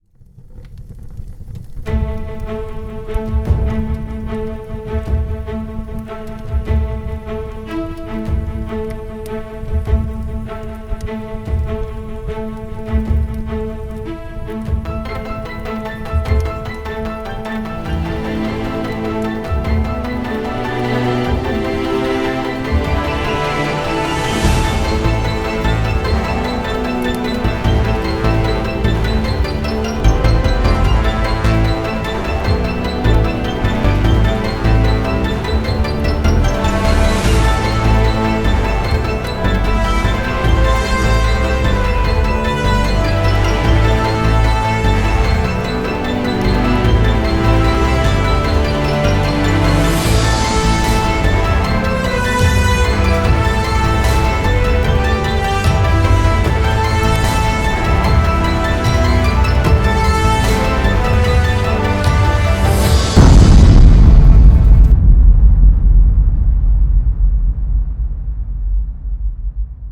bomb-timer-cuenta-atras-de-1-minuto-con-musica-cuenta-regresiva-1-minute-timer-bombloud.mp3